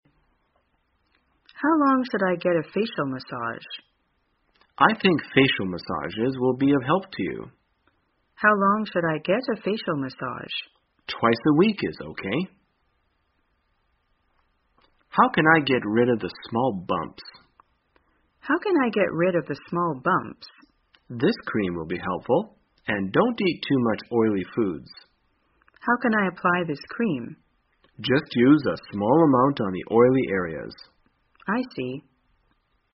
在线英语听力室生活口语天天说 第212期:怎样谈论按摩与除痘的听力文件下载,《生活口语天天说》栏目将日常生活中最常用到的口语句型进行收集和重点讲解。真人发音配字幕帮助英语爱好者们练习听力并进行口语跟读。